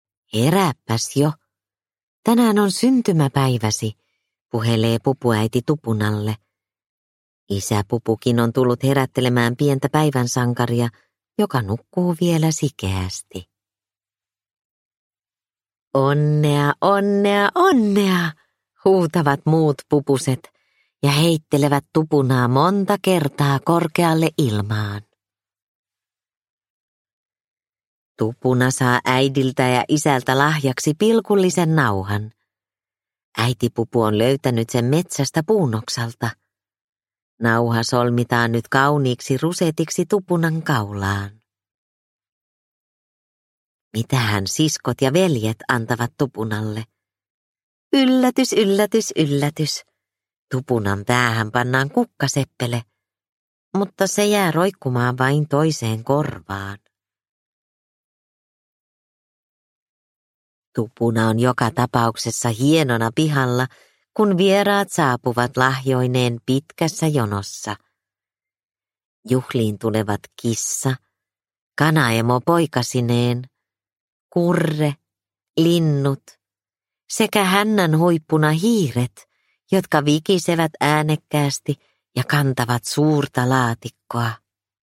Pupu Tupunan syntymäpäivä – Ljudbok – Laddas ner